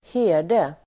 Ladda ner uttalet
herde substantiv, cowherd, shepherd Uttal: [²h'e:r_de] Böjningar: herden, herdar Definition: person som vaktar boskap (a person who tends cattle or sheep) Sammansättningar: fåraherde (shepherd) cowherd substantiv, herde , boskapsherde